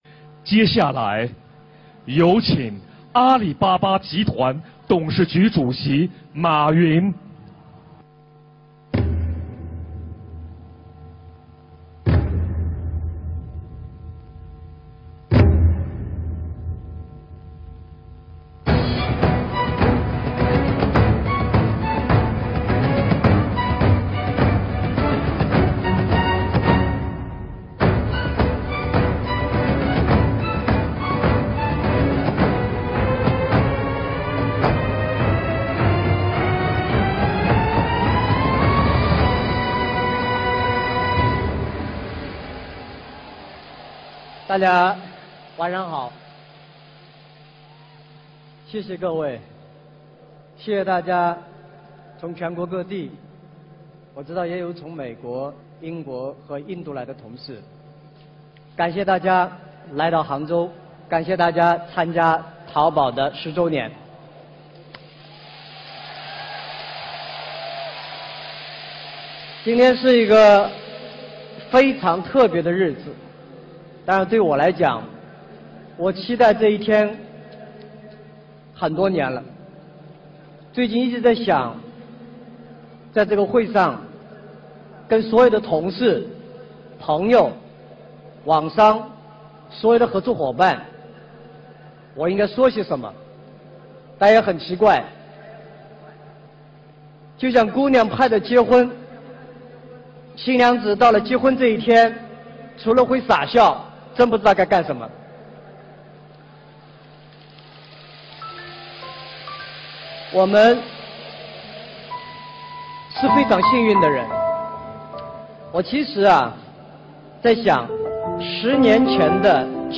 淘宝10周年马云演讲